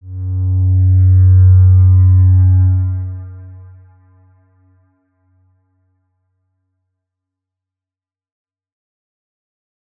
X_Windwistle-F#1-ff.wav